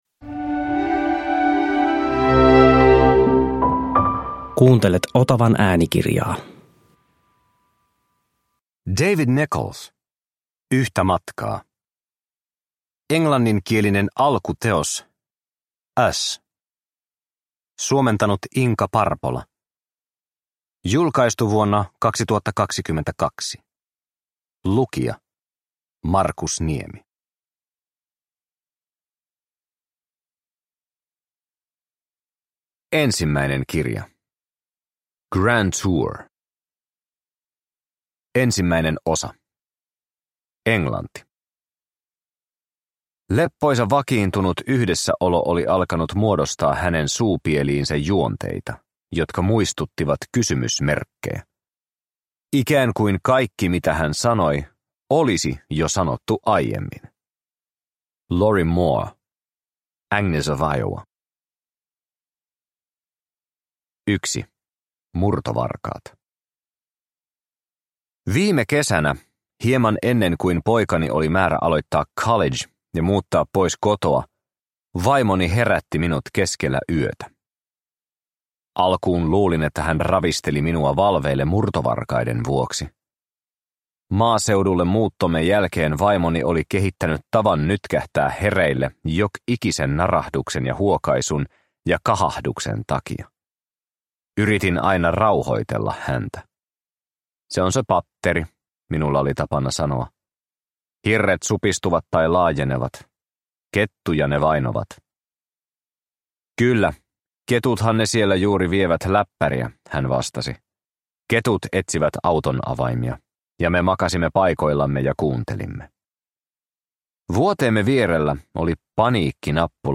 Yhtä matkaa – Ljudbok